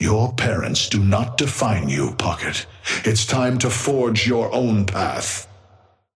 Patron_male_ally_synth_start_04.mp3